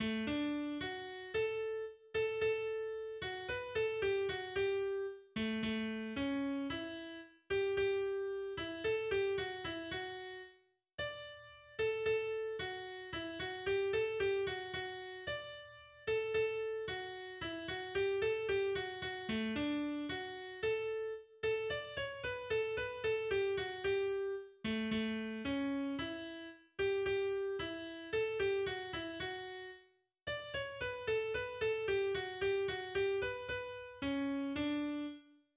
ist ein Weihnachtslied aus dem 19.